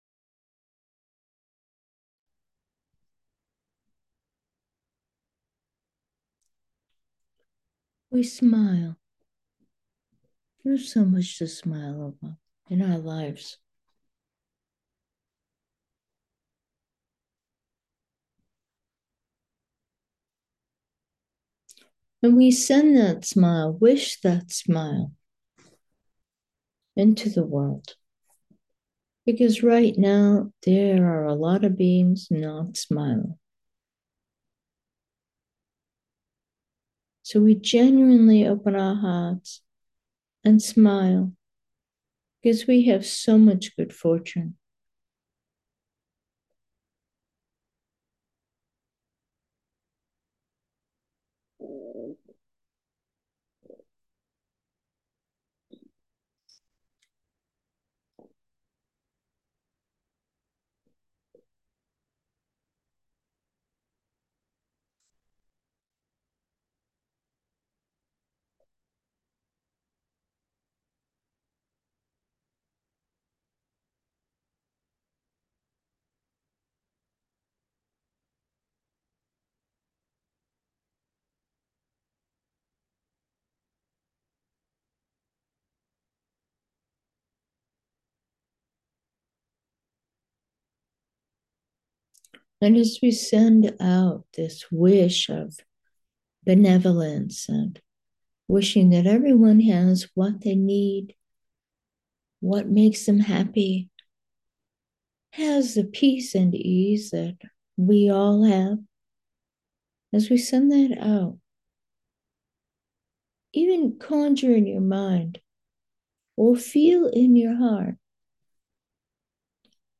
Meditation: awareness, benevolence 3